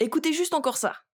VO_ALL_Interjection_06.ogg